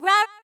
rahRahSisBoomBaBoomS1.ogg